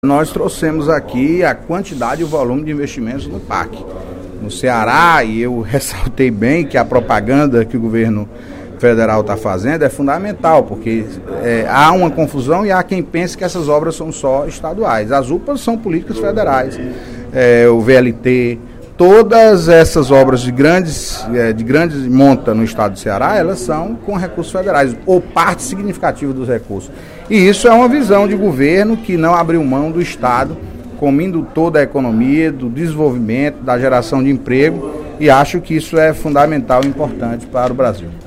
Em pronunciamento durante o primeiro expediente da sessão plenária desta sexta-feira (08/11), o deputado Antonio Carlos (PT) fez um balanço dos investimentos do Programa de Aceleração do Crescimento (PAC) desde sua criação, em 2007.